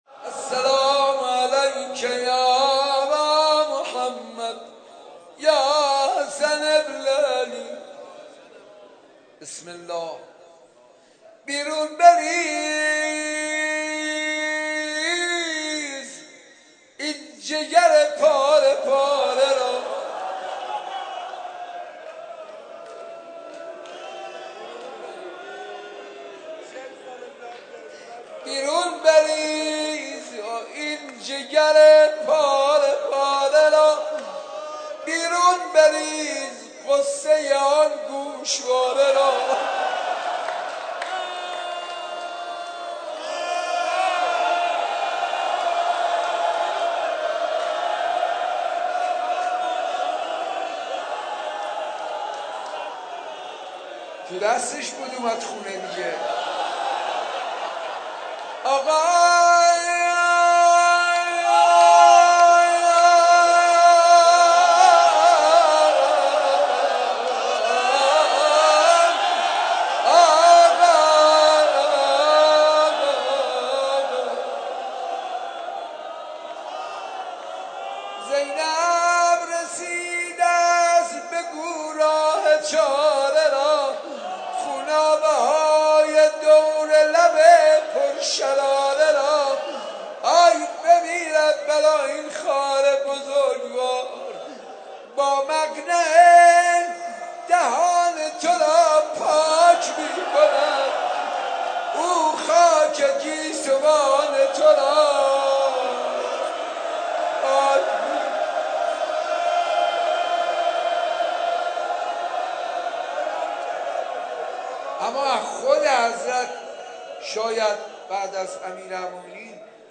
در مسجد ارک برگزار شد